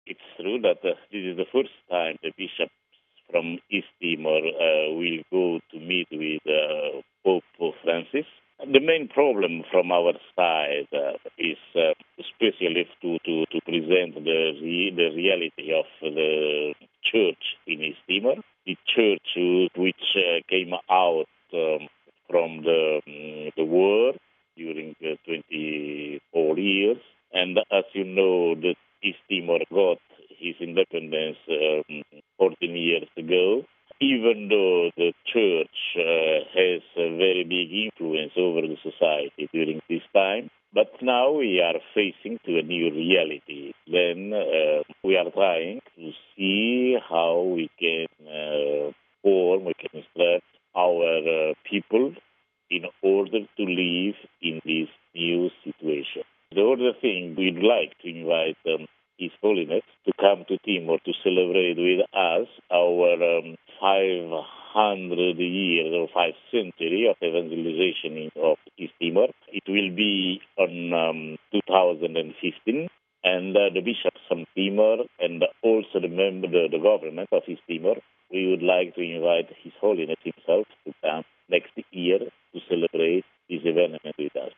Listen to Bishop Basilio Do Nascimento in English: RealAudio